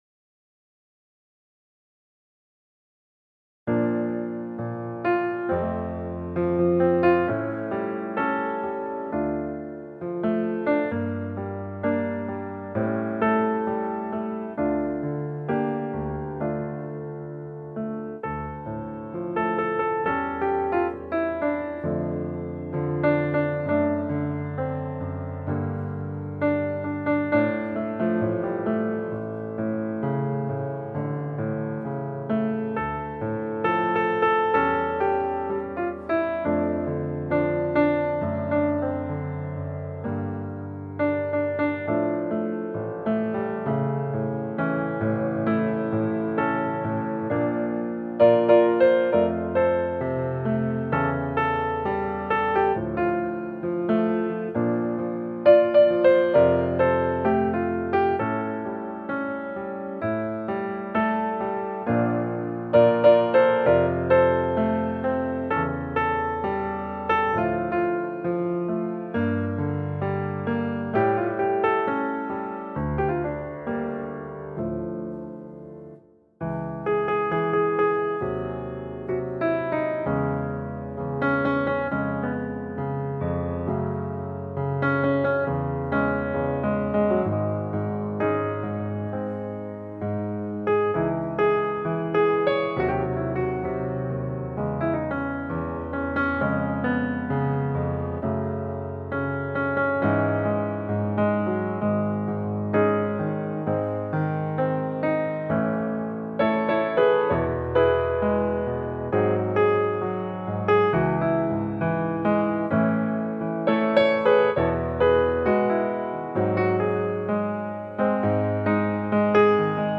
akompaniament